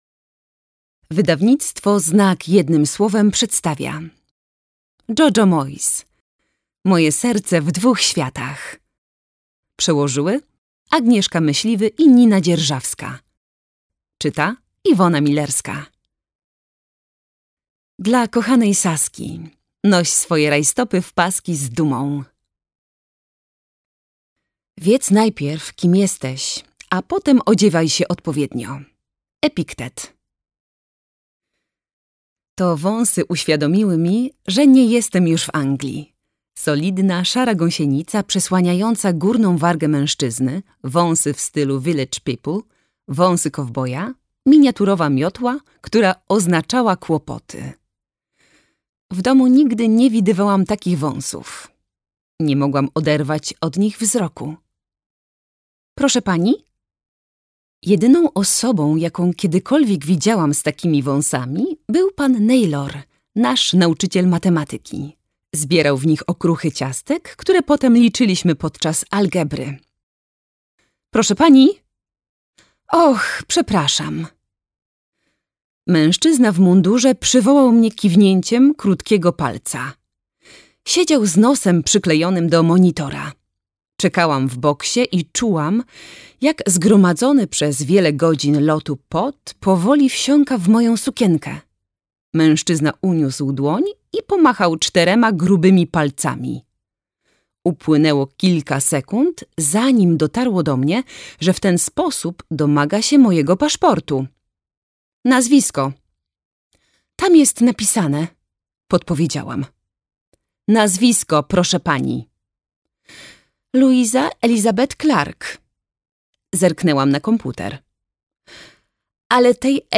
Moje serce w dwóch światach - Moyes, Jojo - audiobook